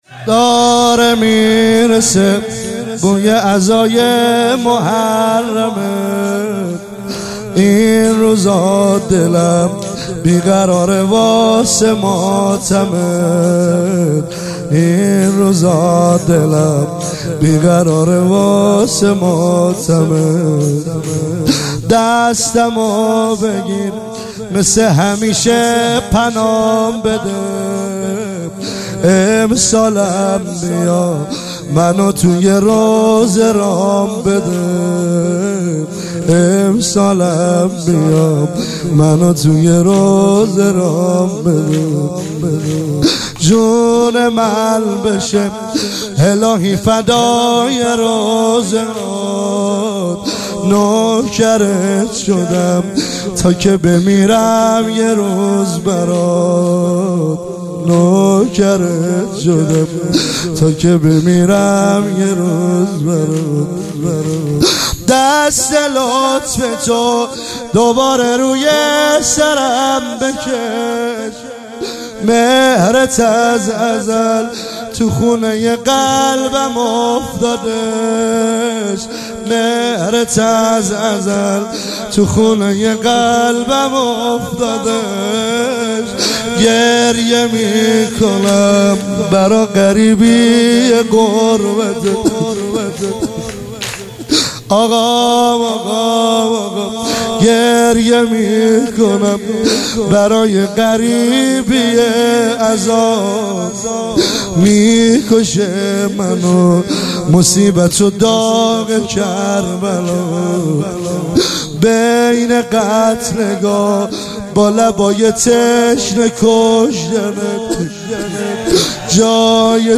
عنوان شب اول محرم الحرام ۱۳۹۸
مداح
برگزار کننده هیئت حسین جان علیه السلام گرگان
شور